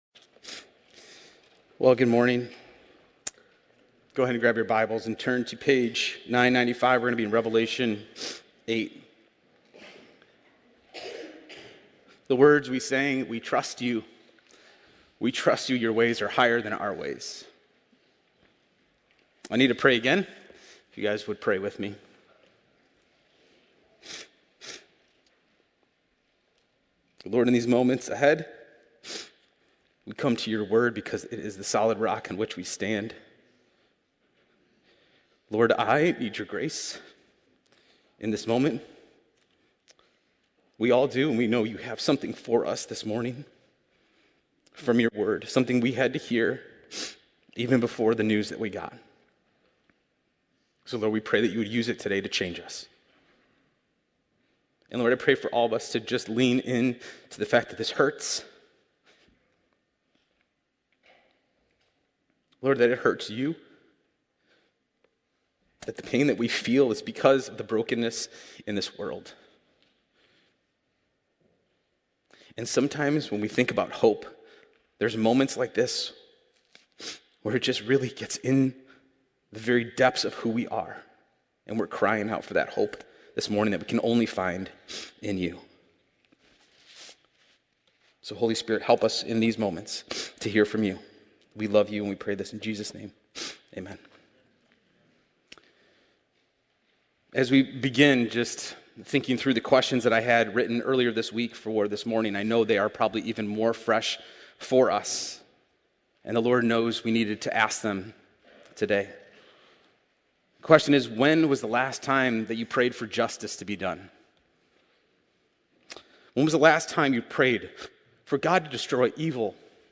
The sermon emphasizes that God’s judgment comes through the prayers of His people. The trumpets in Revelation represent God’s judgment on the earth, which mirrors the plagues in Exodus.